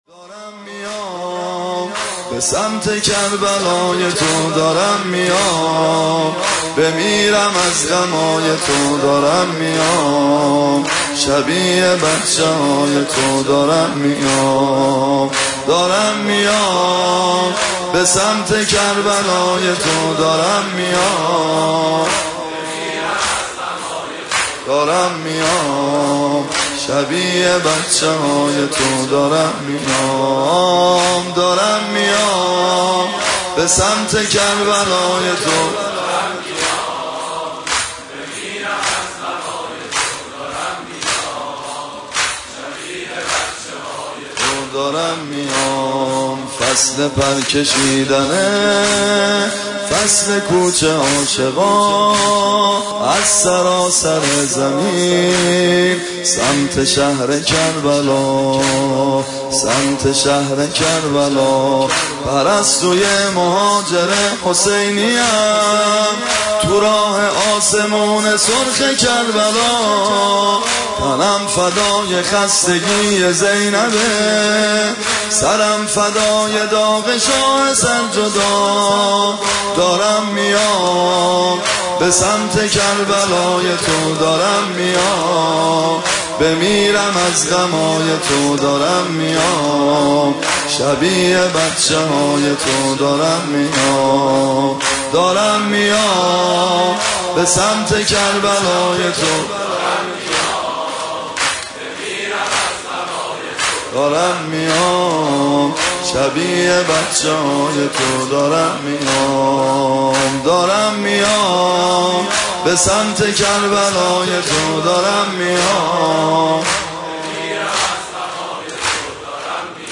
سبک زمینه